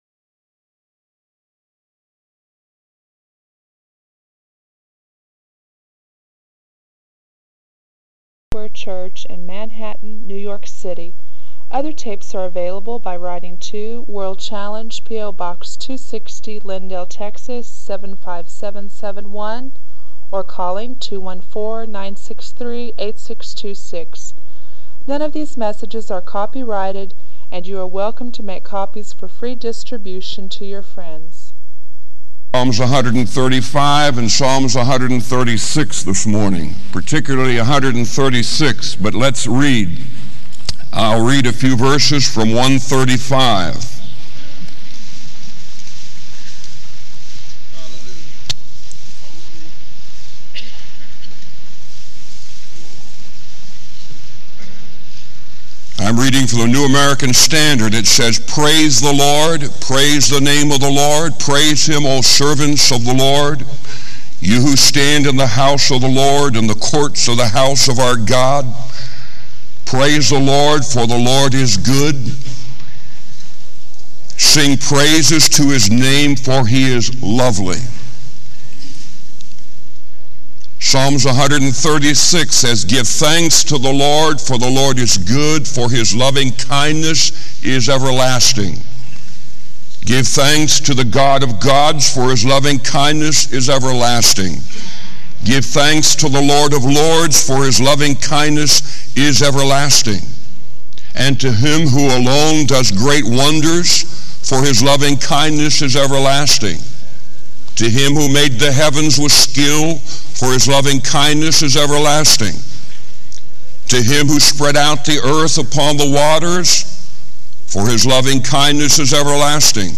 This teaching sermon encourages a heartfelt response of worship and trust in God's unchanging character.